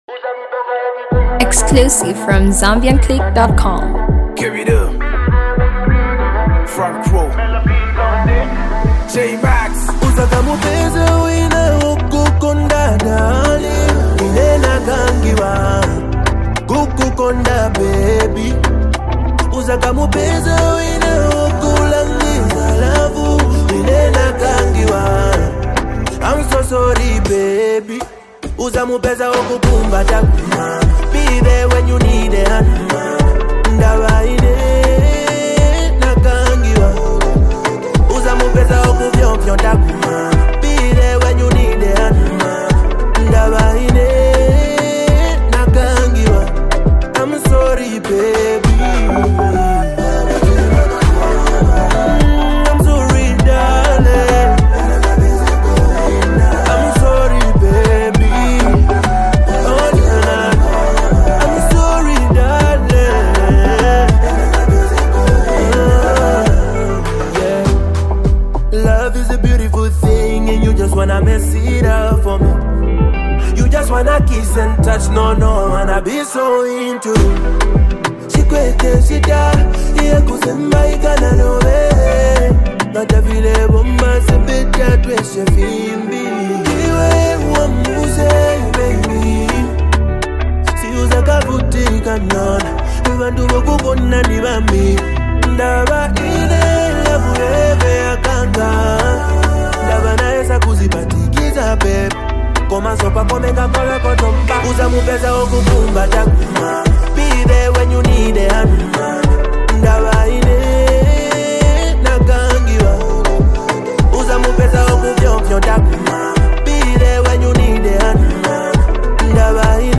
single
Afro swing